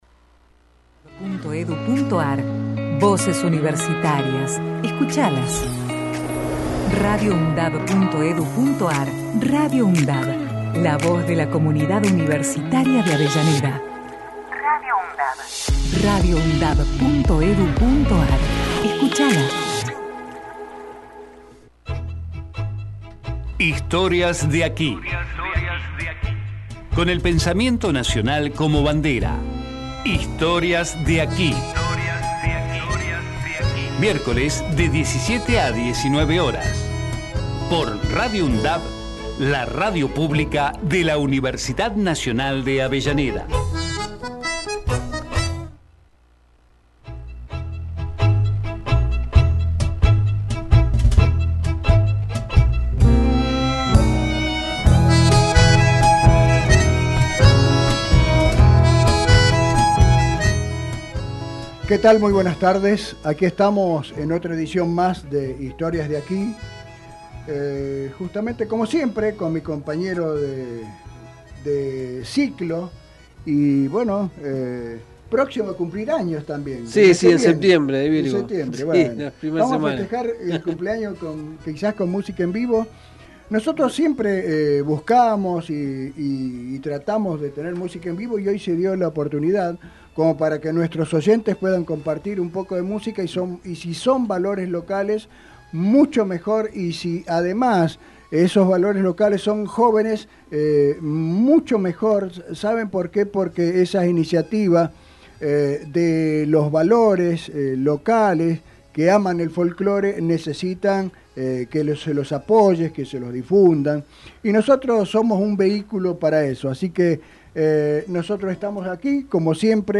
Historias de aquí Texto de la nota: Historias de aquí Con el pensamiento nacional como bandera. Música regional, literatura y las historias que están presentes en la radio.